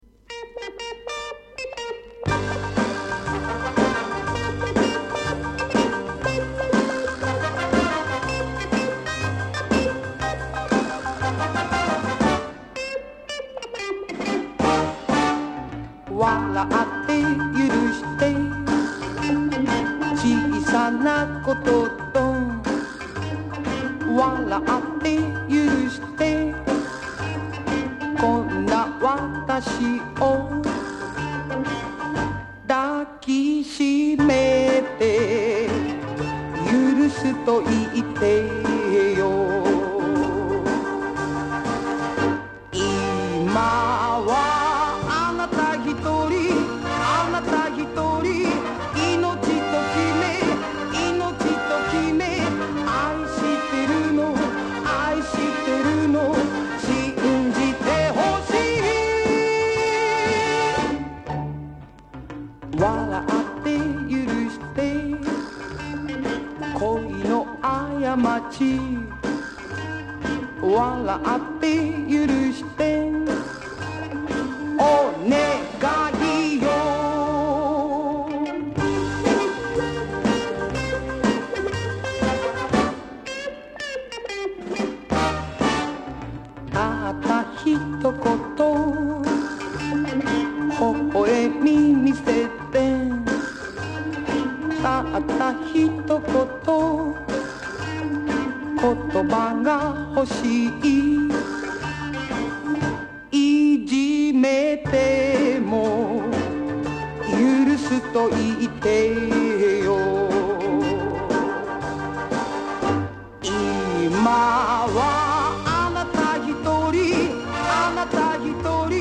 盤に薄いスリキズ有/音の薄い部分で若干チリノイズ有
DJプレイ可能な和モノ作品の多い和製リズム・アンド・ブルーズ歌謡女王